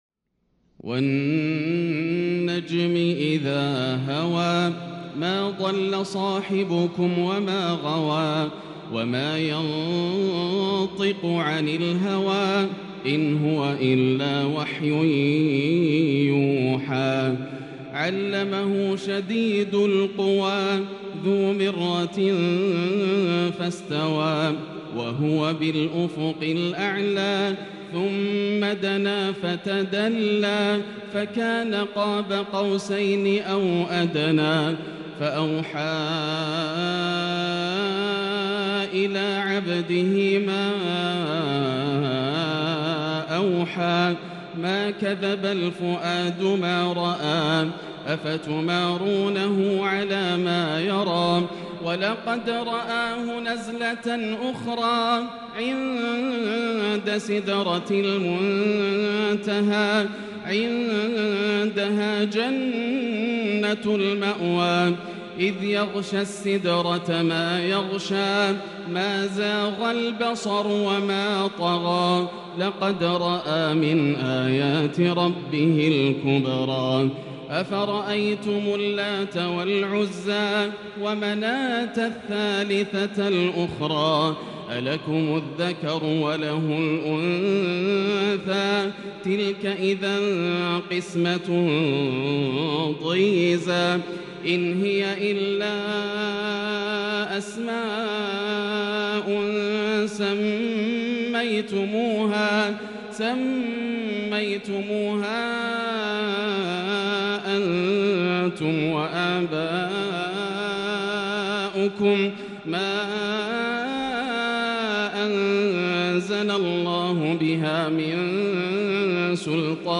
صلاة العشاء 1-6-1442هـ سورة النجم | Isha prayer from Surat An-Najm 14/1/2021 > 1442 🕋 > الفروض - تلاوات الحرمين